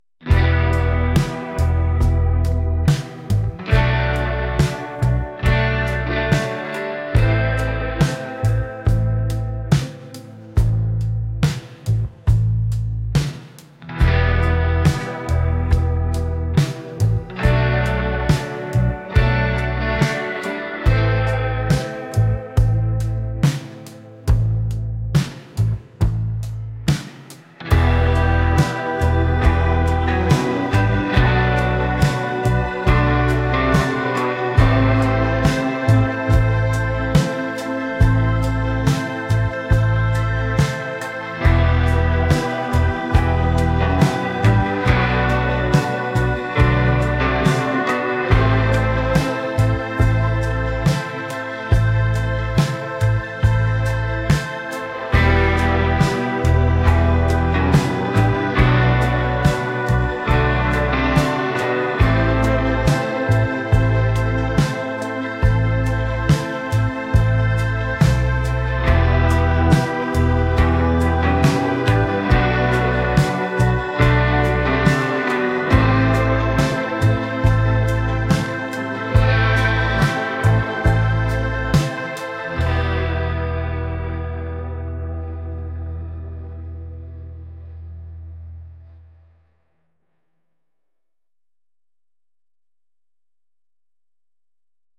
• Indie
• R'n'b
• Rock
• Soul
Solo
Intim og emotionel oplevelse